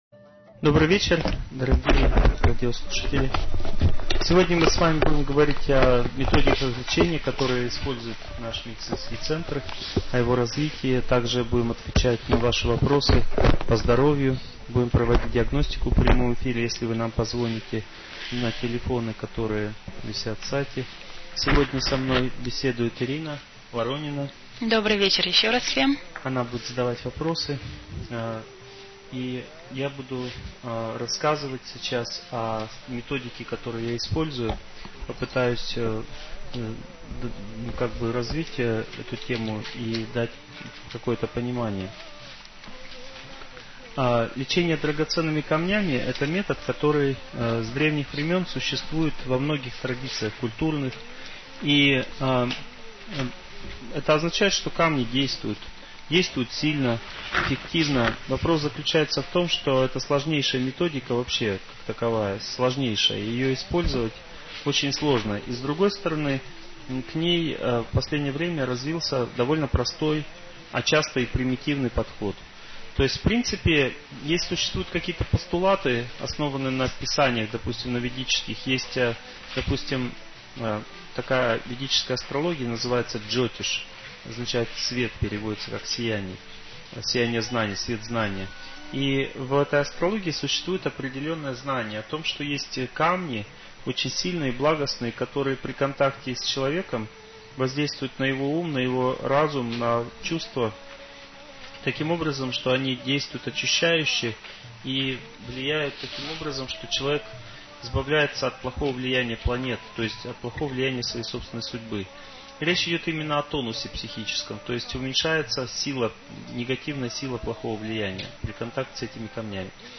Лечение драгоценными камнями (прямой эфир на «Аюрведа-радио», 2008)